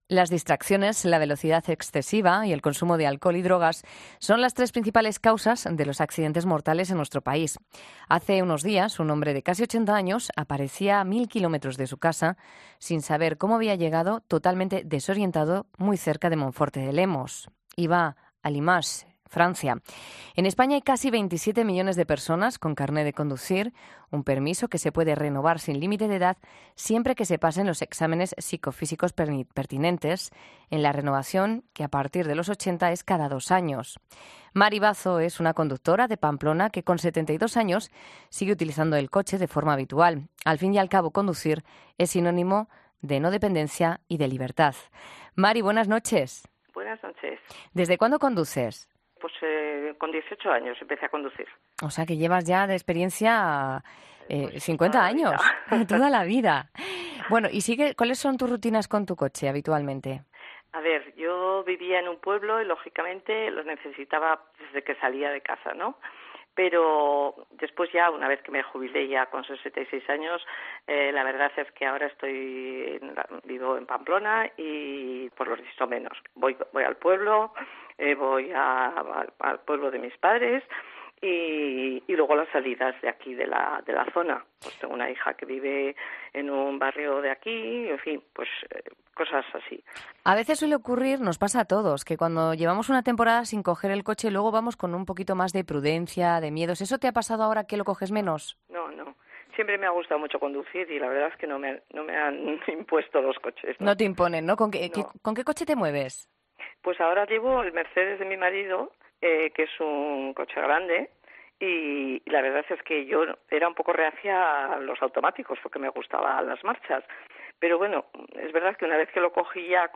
ESCUCHA ESTAS ENTREVISTAS EN 'LA NOCHE' La edad no es el único factor que nos puede hacer peligrososos al volante.